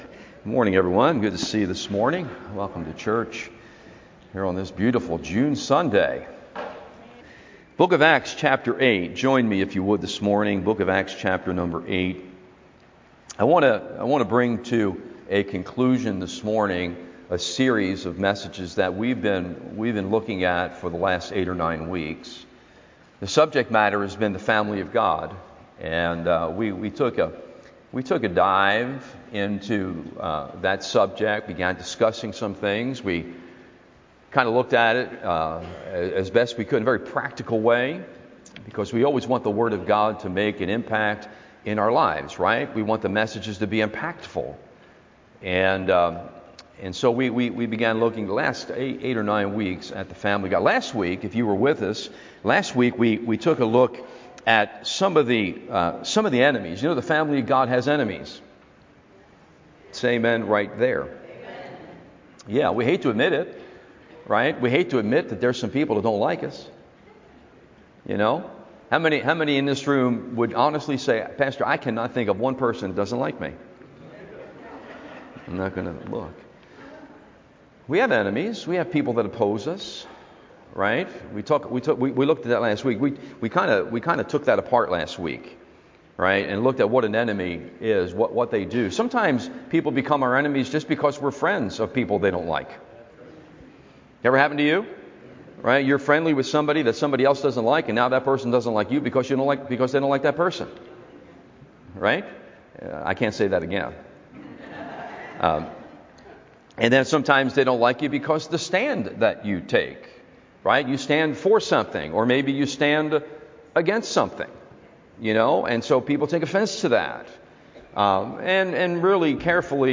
The Family of God Sunday AM Service